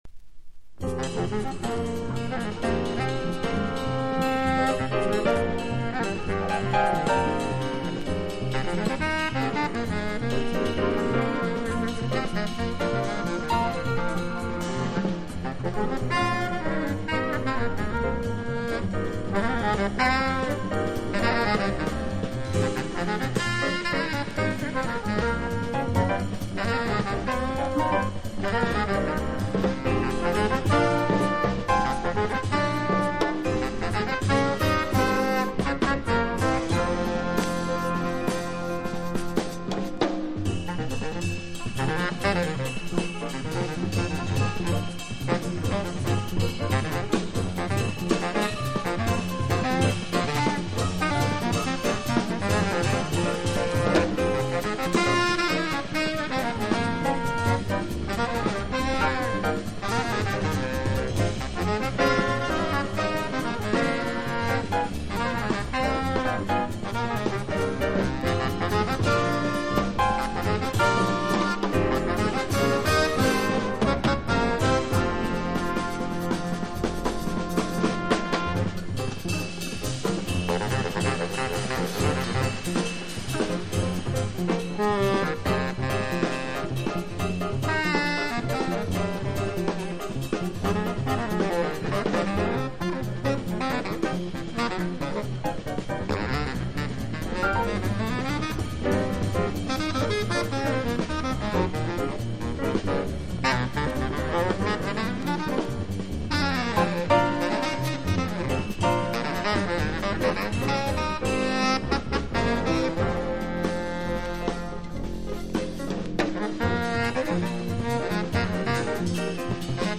Genre US JAZZ